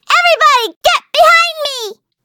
voices / heroes / en
Taily-Vox_Skill2_c.wav